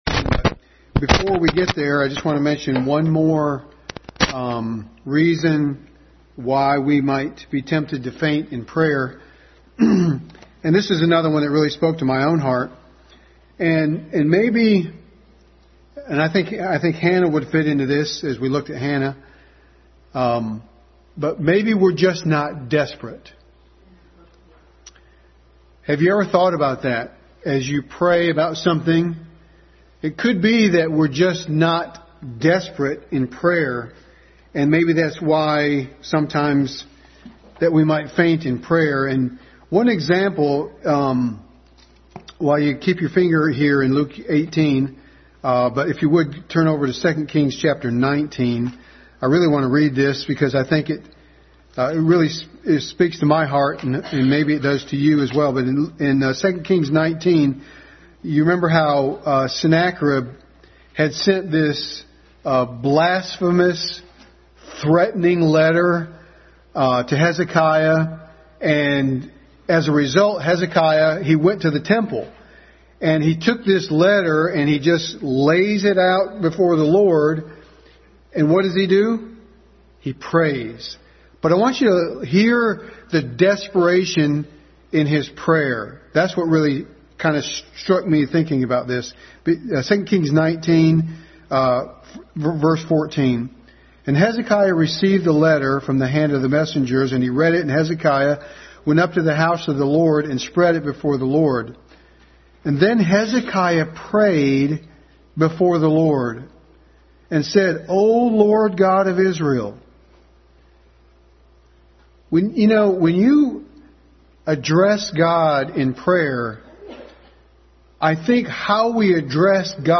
Passage: Luke 18:1-8 Service Type: Family Bible Hour